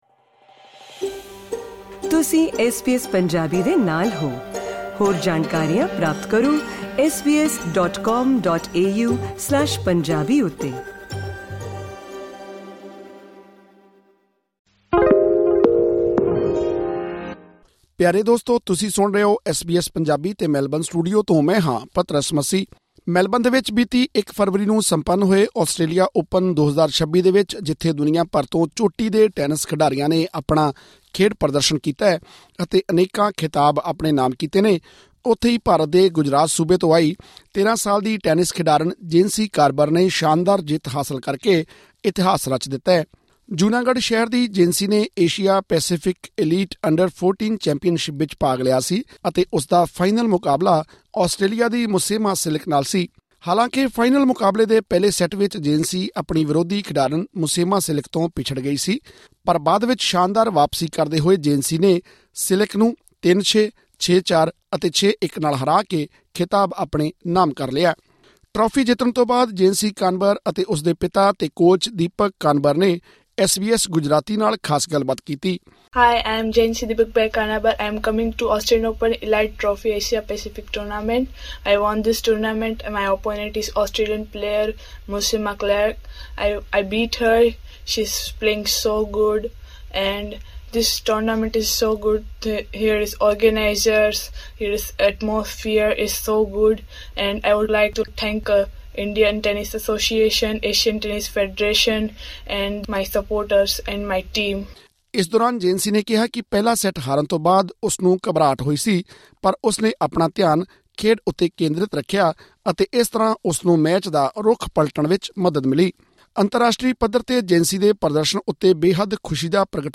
ਹੋਰ ਵੇਰਵਿਆਂ ਲਈ ਸੁਣੋ ਇਹ ਰਿਪੋਰਟ…